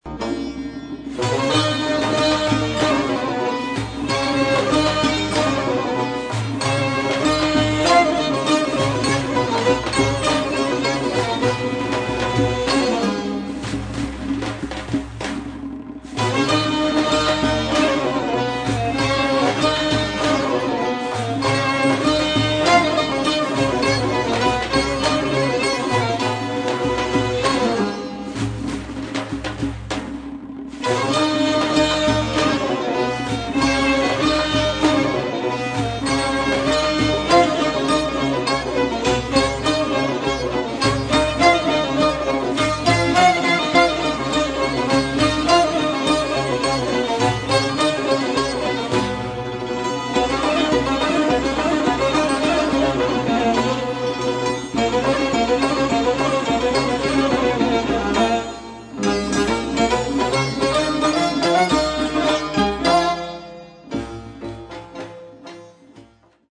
قسمتی از ضربی ابوعطا